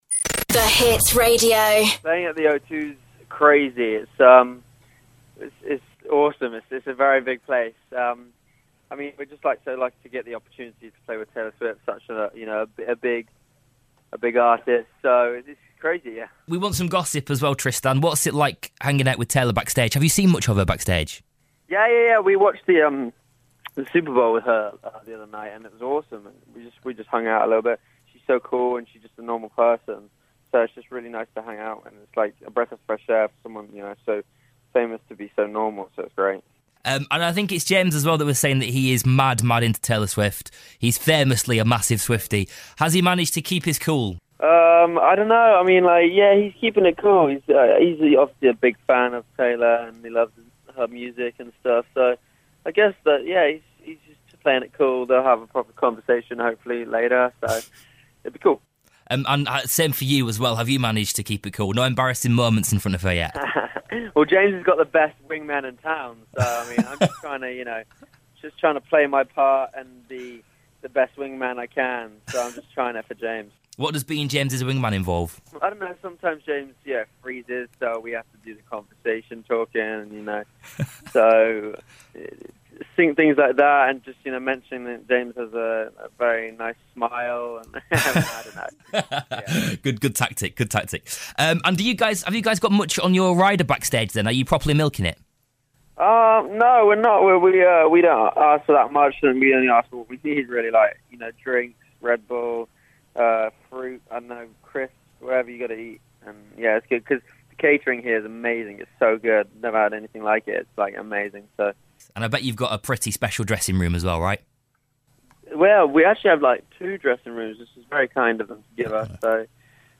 Tristan From The Vamps on the Phone